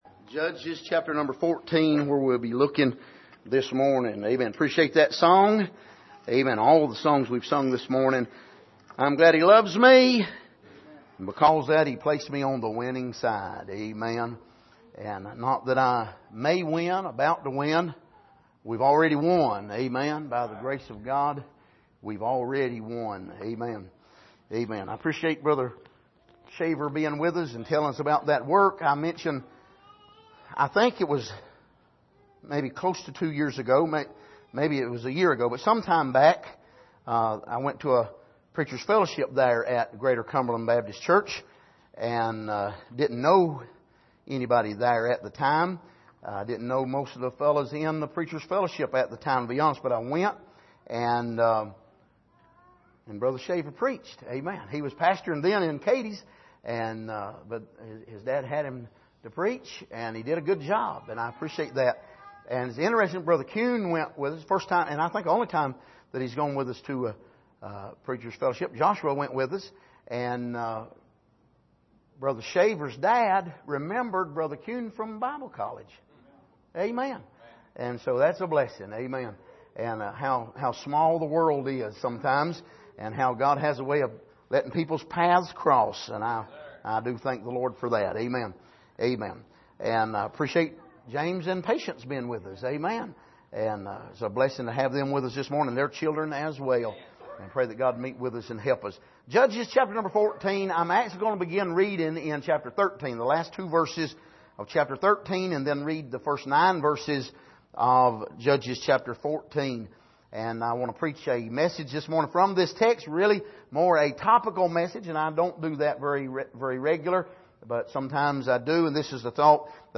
Passage: Judges 14:1-9 Service: Sunday Morning